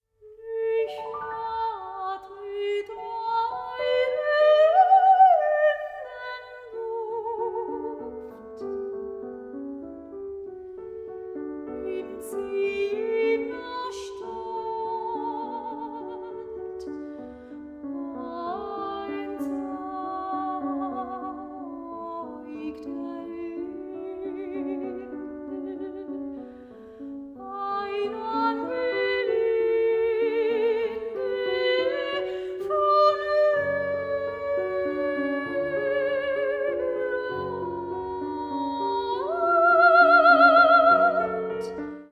The climax was superb, and so was the accompaniment.
VOCAL MUSIC
PIANO MUSIC